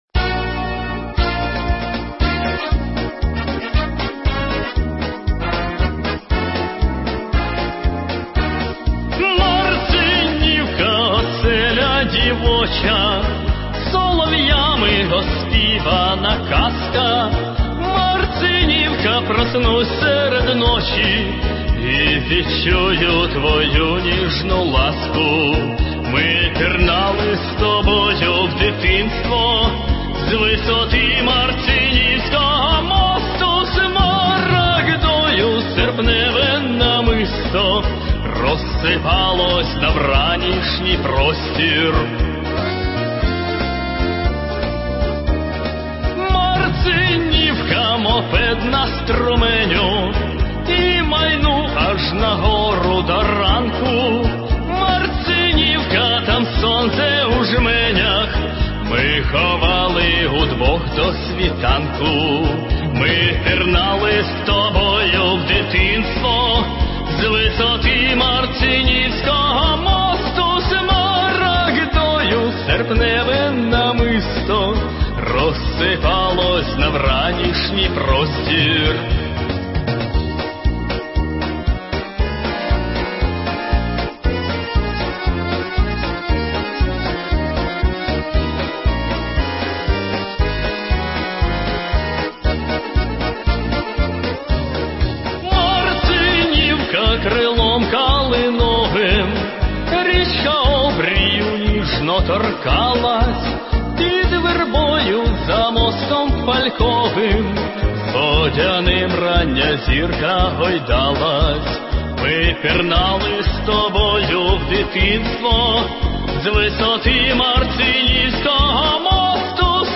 Слова и музыка Алексея Мозгового. И поёт тоже он, автор